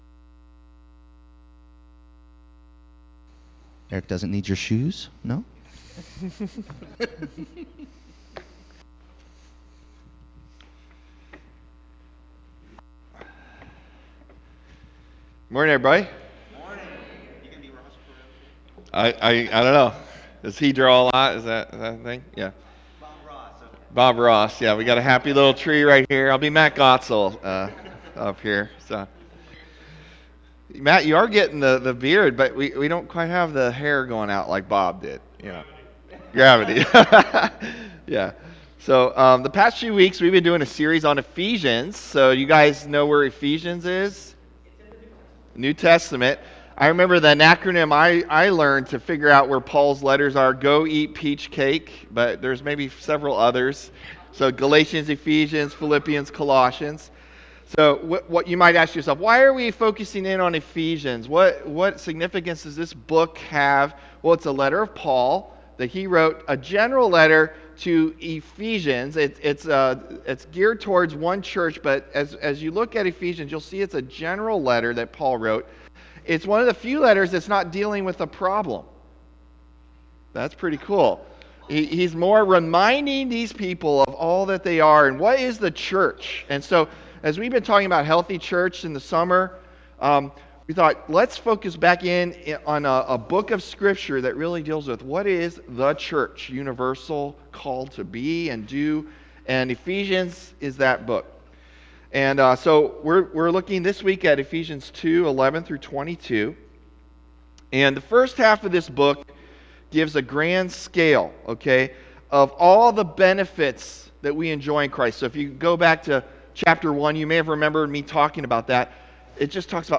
October 1 Sermon | A People For God